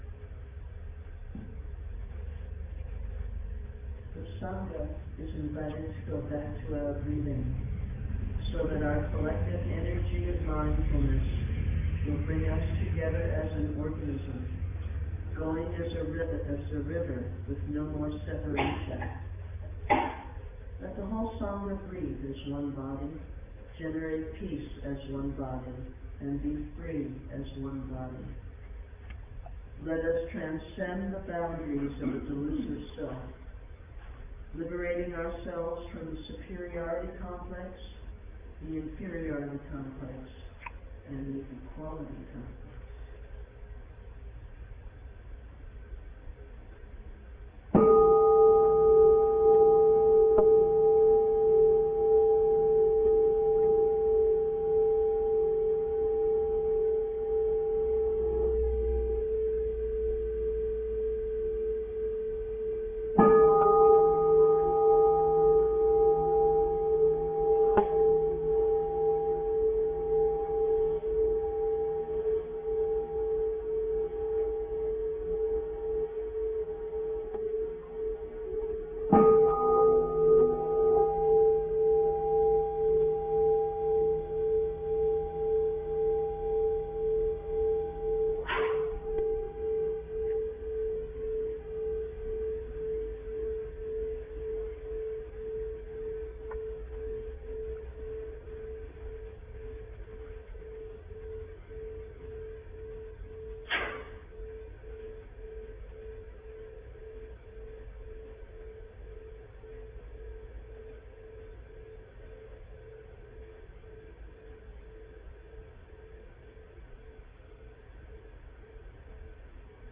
Sangha Talks | February, March 2011
Mar 6 2011 Naples Sangha Metta Meditation and Precious Sun.mp3